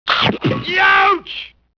Snap Youch From the Flintstones
youch.wav